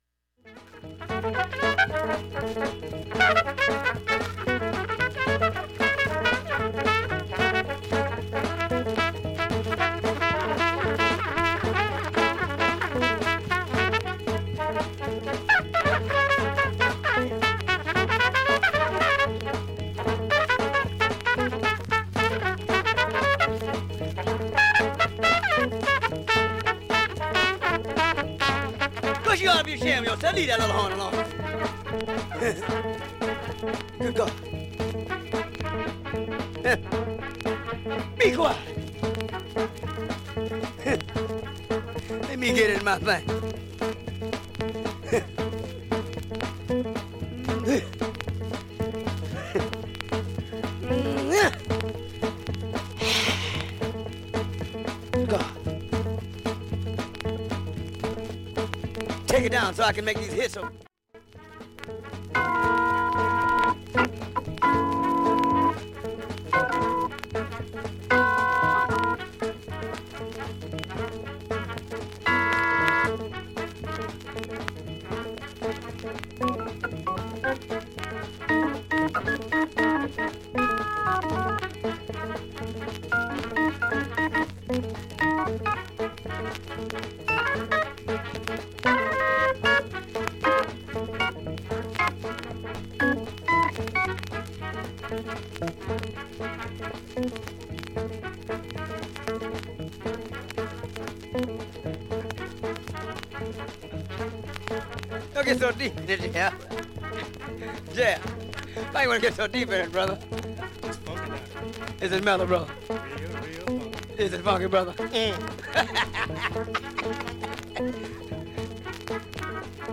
かすかにバックチリ出ていますが
周回プツ、プツプツ出ますがかすかです。
B-1始めにかすかなプツが７回と７回出ます。
コリコリの音質のギターで奏でる
ファンキーな作品